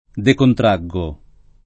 decontrarre [ dekontr # rre ] v.;